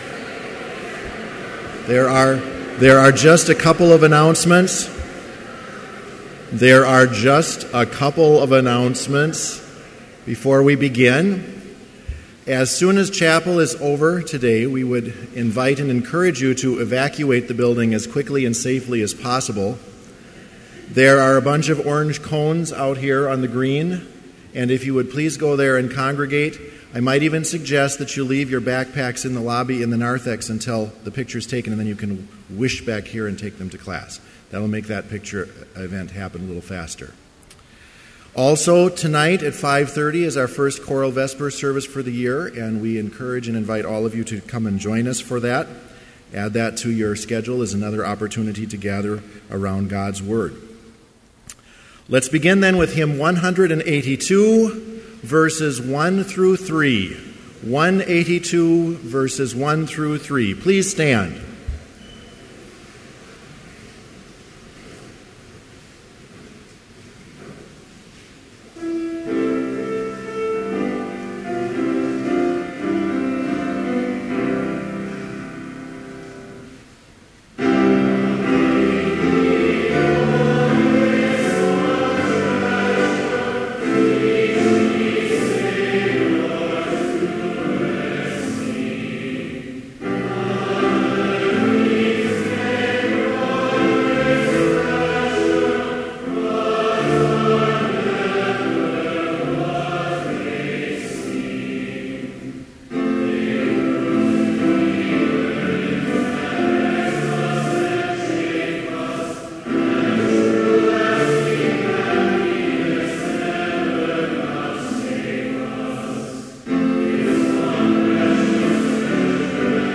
Complete service audio for Chapel - August 27, 2014